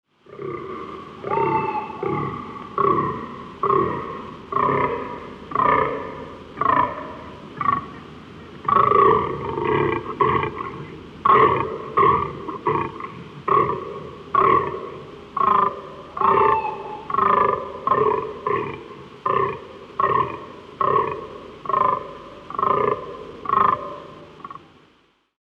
Звуки животных